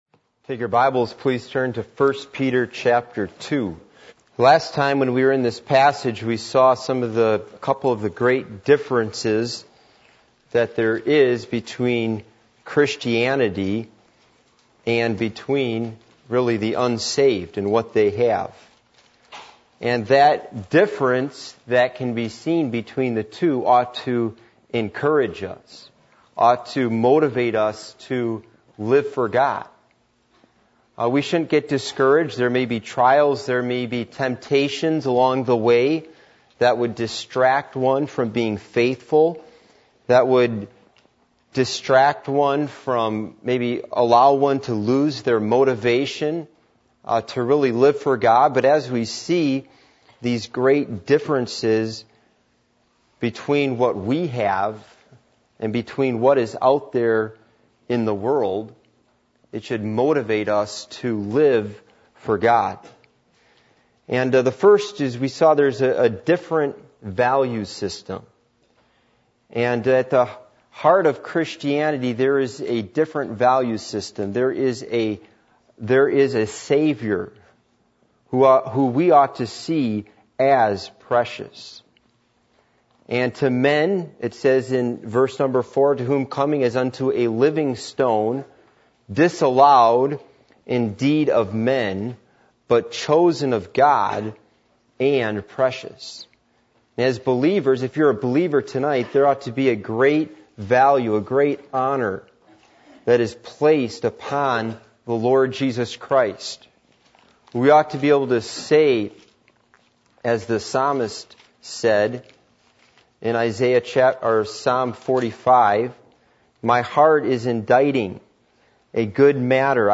1 Peter 2:6-11 Service Type: Midweek Meeting %todo_render% « Why Do People Need A Leader?